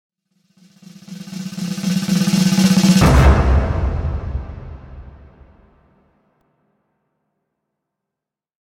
Dramatic Build-Up Drum Roll Sound
Description: Dramatic build-up drum roll sound. Experience a dramatic drum roll with a gradual build-up and a powerful crescendo.
Use this intense drum sound effect to make your content more exciting and engaging.
Dramatic-build-up-drum-roll-sound.mp3